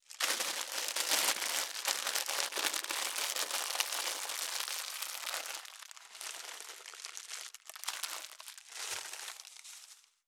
2025年3月2日 / 最終更新日時 : 2025年3月2日 cross 効果音
657コンビニ袋,ゴミ袋,スーパーの袋,袋,買い出しの音,ゴミ出しの音,袋を運ぶ音,